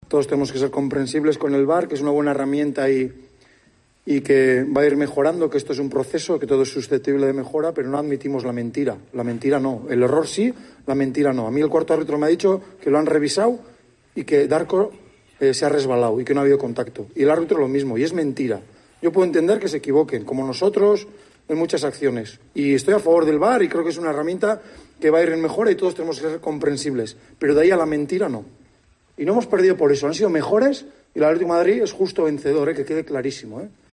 "El VAR es una buena herramienta, pero no aguantamos la mentira, el error sí", señaló el entrenador de Osasuna tras caer en el Wanda Metropolitano.